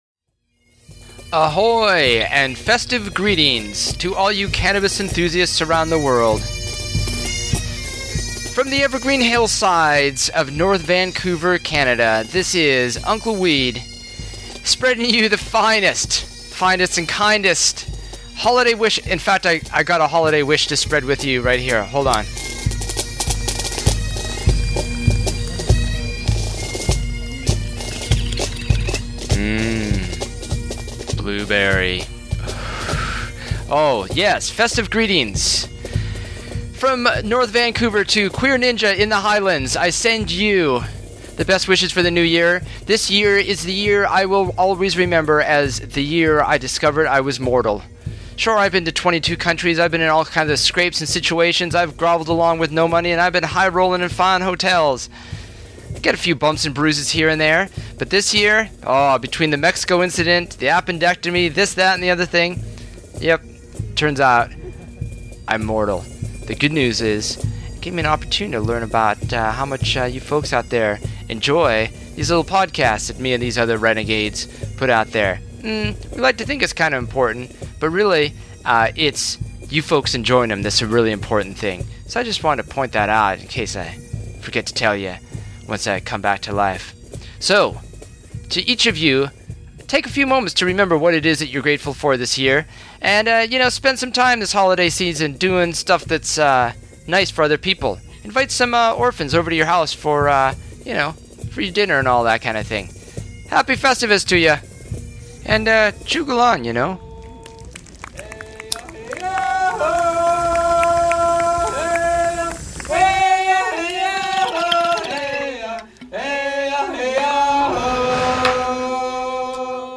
with bagpipes!